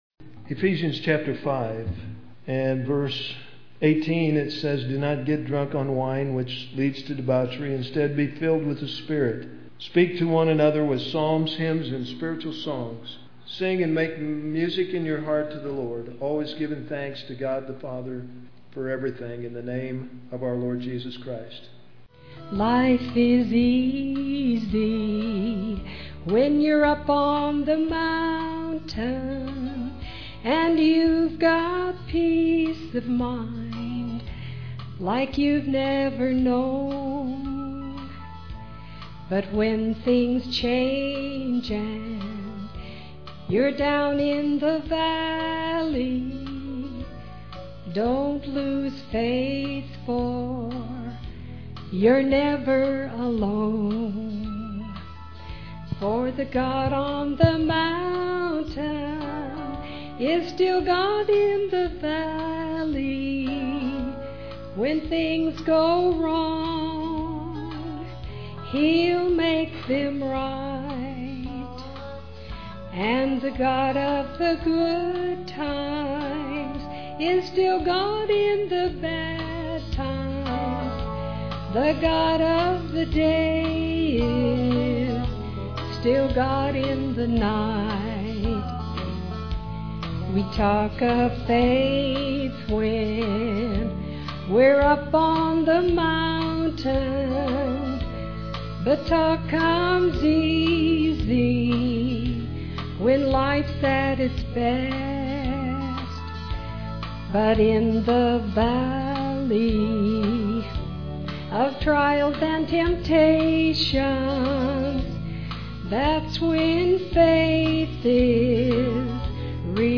Vocal solo
piano accompaniment
Guitar and vocal solos
Vocal and piano solo
Vocal duet